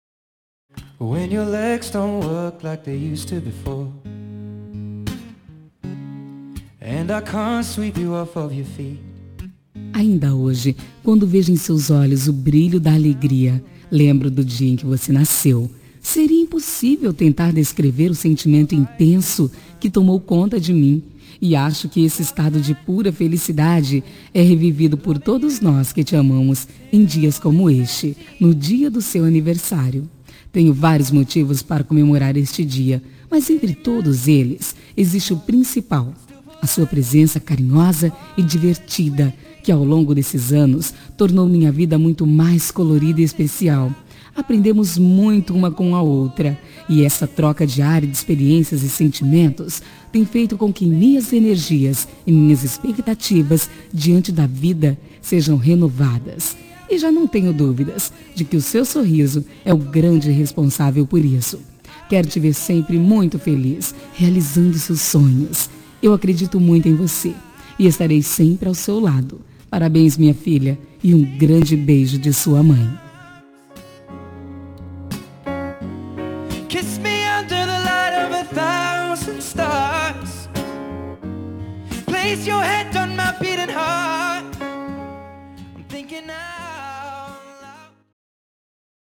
Telemensagem de Aniversário de Filha – Voz Feminina – Cód: 1758